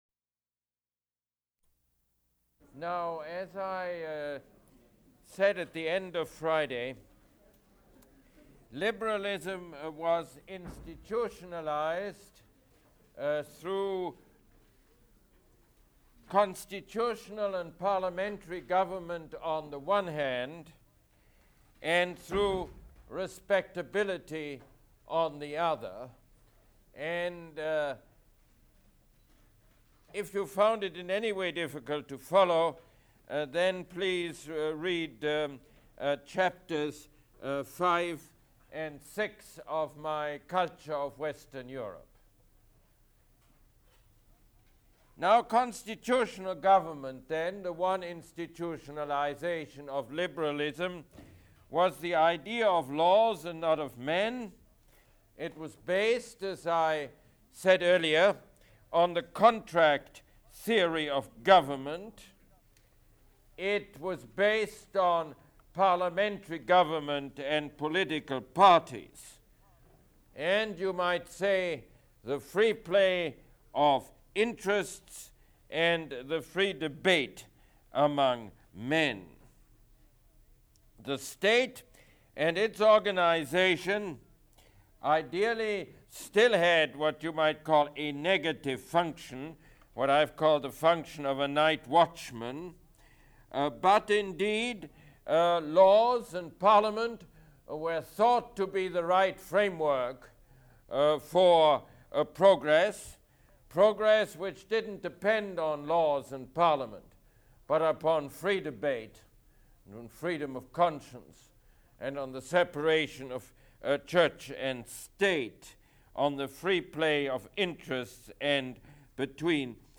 Mosse Lecture #25